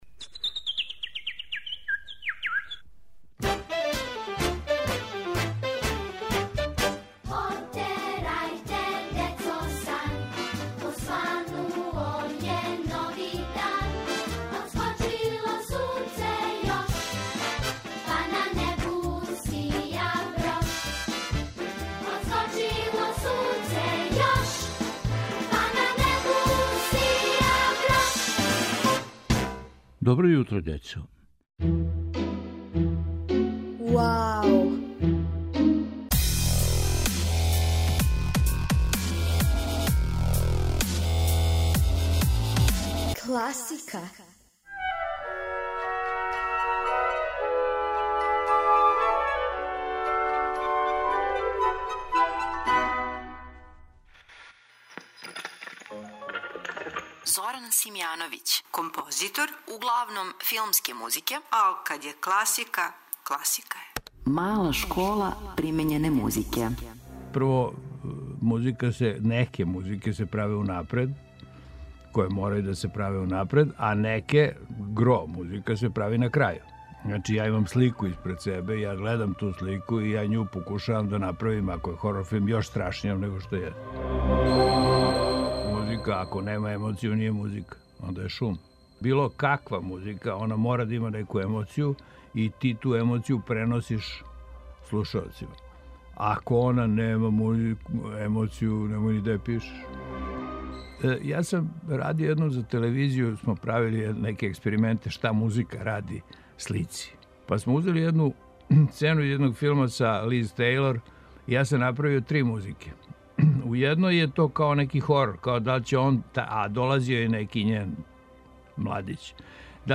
Послушајте причу композитора Зорана Симјановића у нашем серијалу "Вау, класика!"